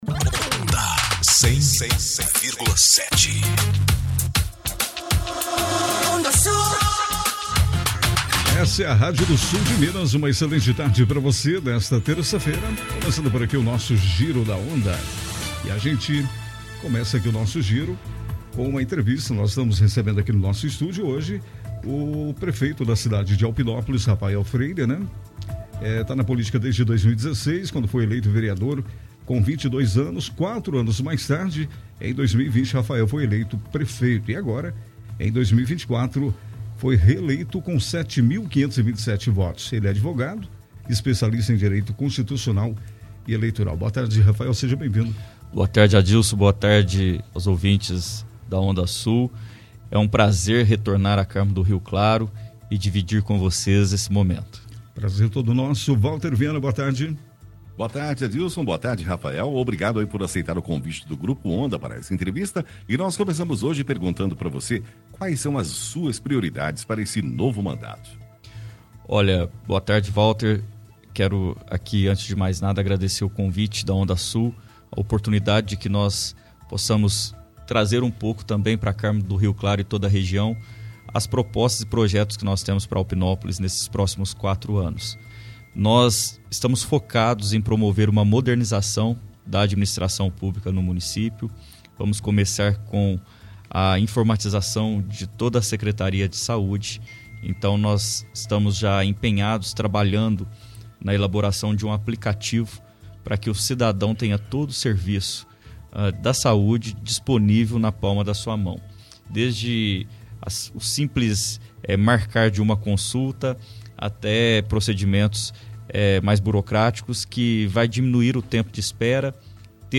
Nesta terça-feira, (14), O prefeito de Alpinópolis, Rafael Freire, foi destaque em entrevista ao programa Giro da Onda, onde compartilhou as realizações de sua primeira gestão e os projetos ambiciosos para o novo mandato.
Entervista-Rafhael.mp3